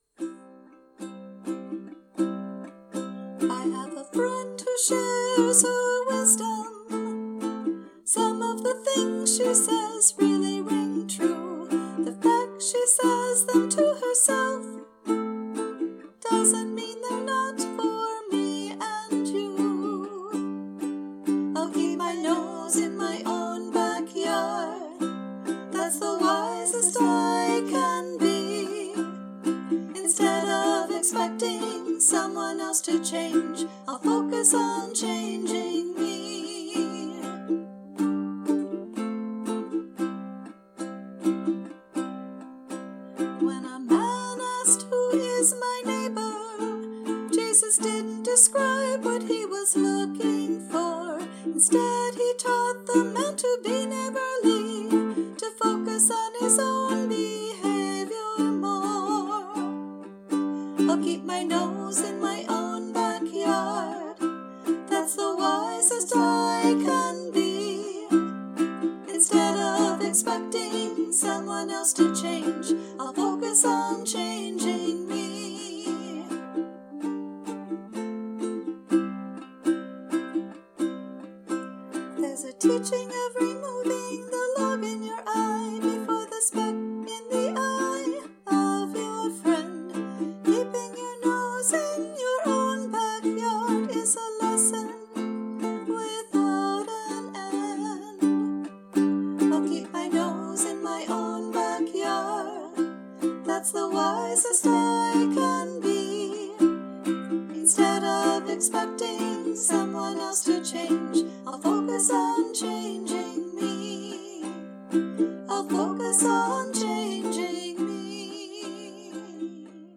I like the unexpected chords in the chorus.
Bonus - great harmony in the chorus!